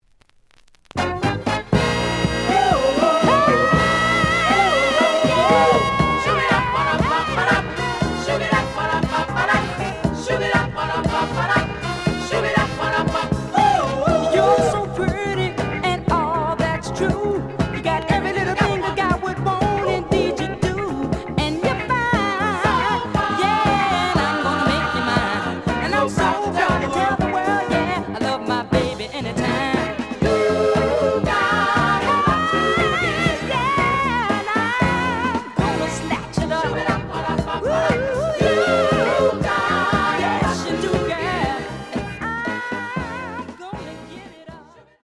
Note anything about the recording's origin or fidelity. The audio sample is recorded from the actual item. B side plays good.)